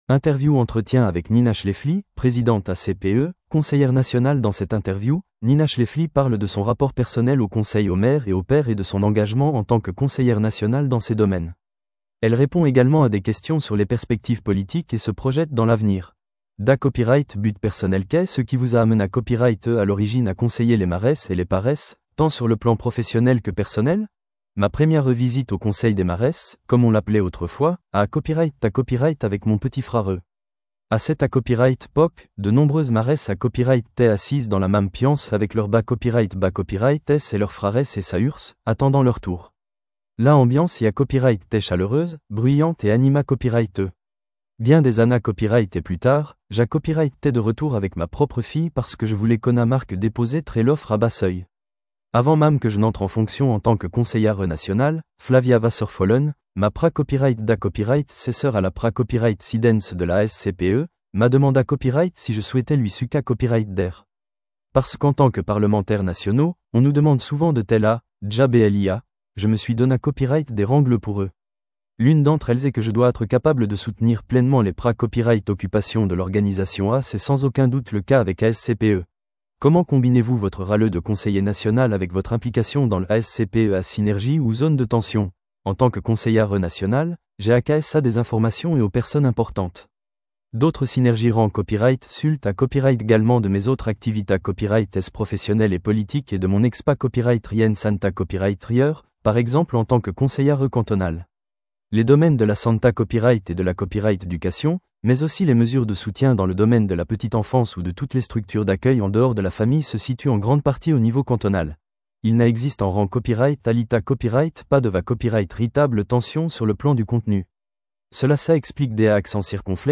Entretien avec Nina Schläfli, Présidente as-cpe, Conseillère nationale | Clic